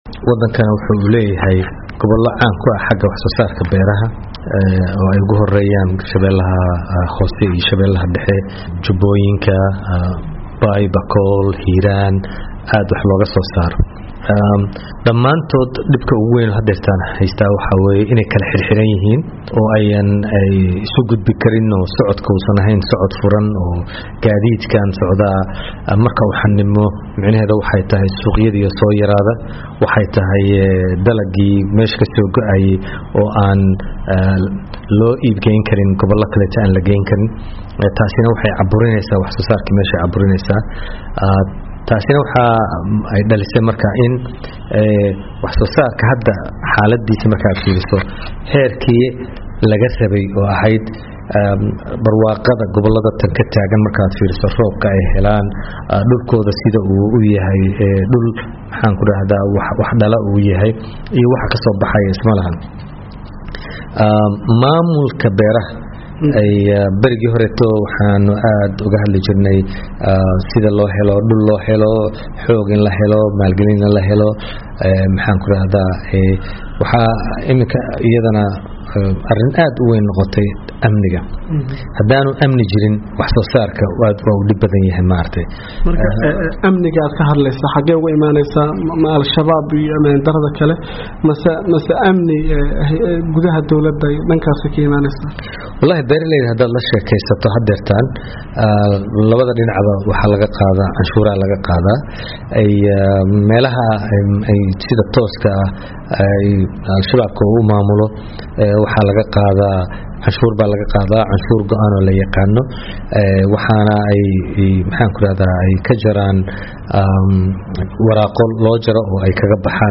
Wareysi uu siiyey VOA-da, ayuu Cabdi Axmed Baafo ku sheegay in marka la eego barwaaqada dalka iyo roobabka da’ay, aysan beeraha Soomaaliya wax soo saarkooda gaarsiisneyn meeshii laga filayey.